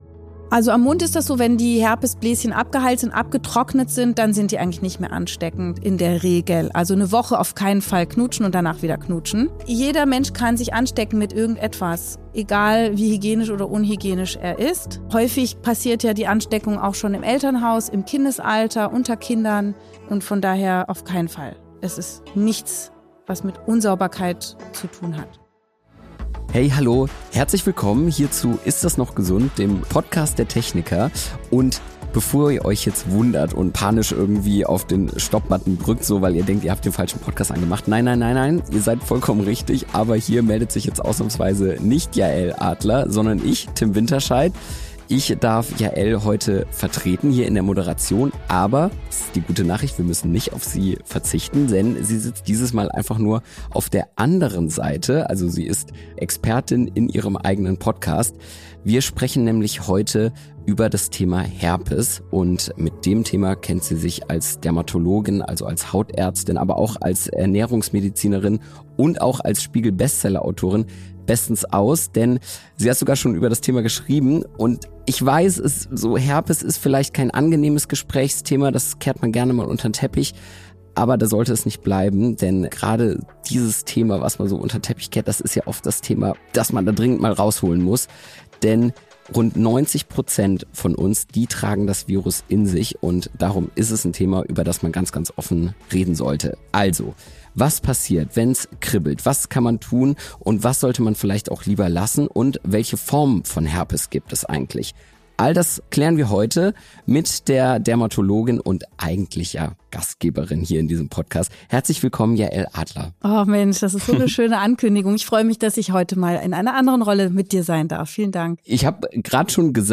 Warum das so ist und was wirklich hilft, wenn sich ein Herpes-Ausbruch ankündigt, erklärt uns die Dermatologin Dr. Yael Adler. **Im Gespräch